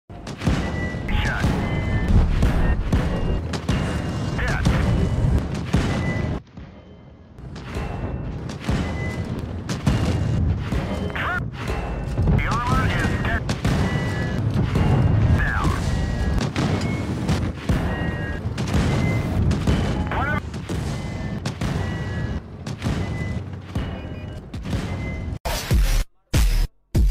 Sound ricochet active 🐢 sound effects free download